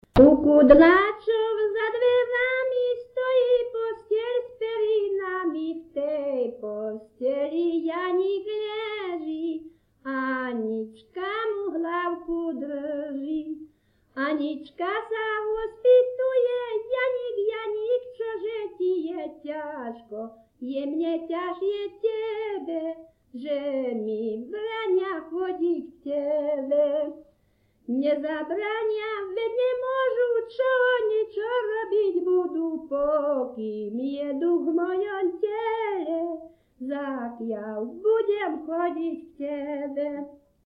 Descripton sólo ženský spev bez hudobného sprievodu
Place of capture Litava
Key words ľudová pieseň